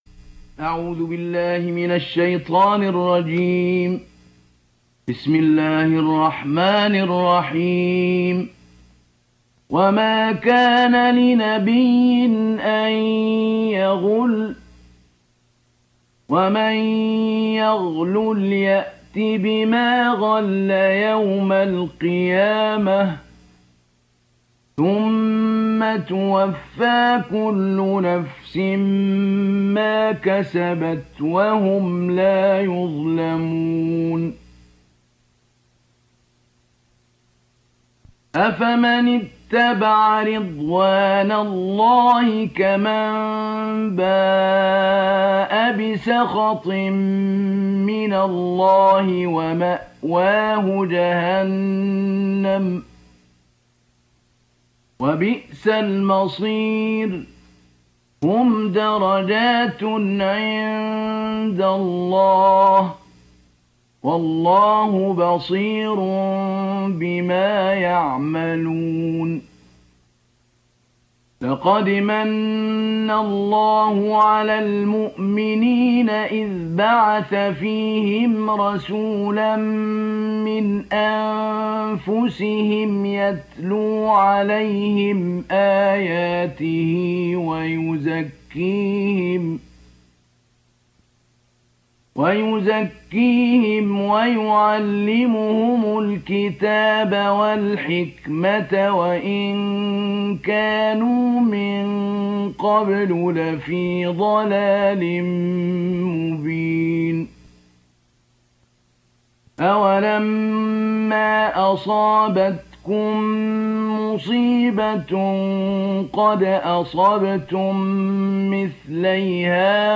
سجل الشيخ محمود البنا رحمه الله هذا المصحف المرتل النادر للاذاعة السعودية في أواخر أيامه فقد توفي بعد تسجيلة بمدة قصيرة ( بضعة اسابيع ) وهو طبعا مختلف عن مصحفه الآخر المسجل للاذاعة المصرية